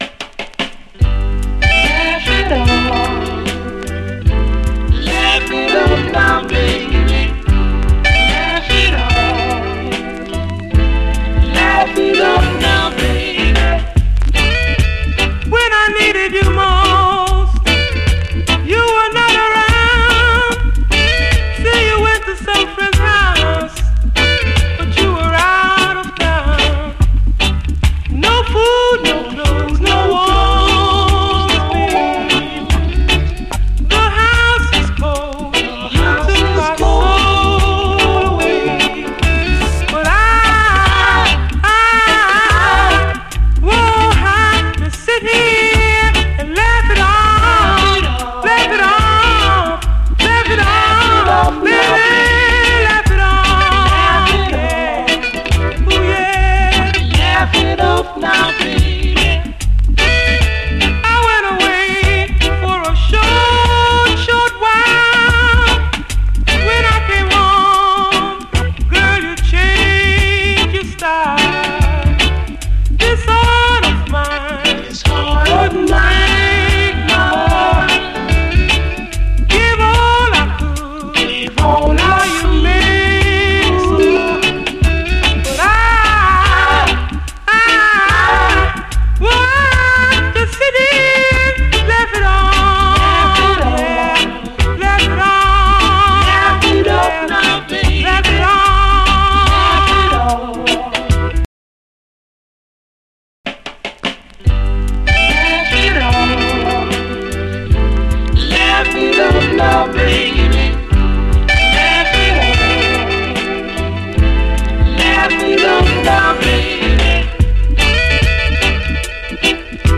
REGGAE, 7INCH
ファルセット・ヴォーカルが甘く切なくこだまする哀愁スウィート・レゲエ！
微妙にエフェクトのかかったコーラスがよい。